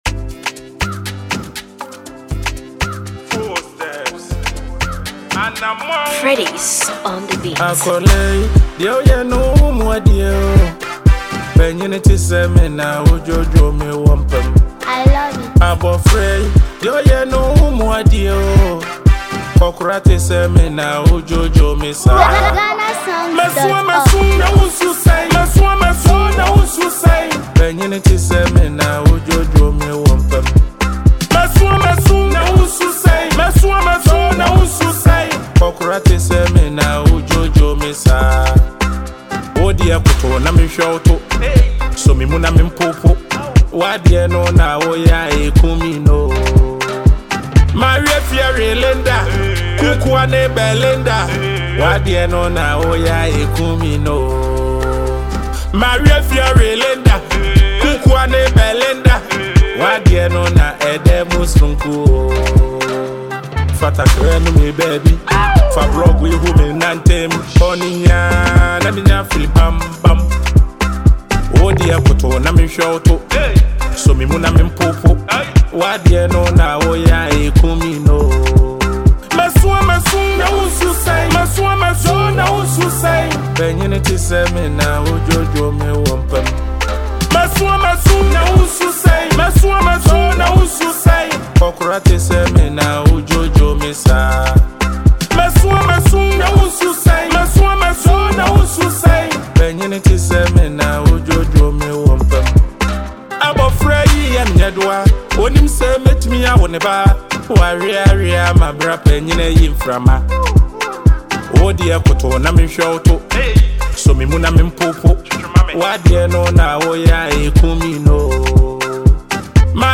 catchy rhythm and witty lyrics
• Real-life message wrapped in humor and rhythm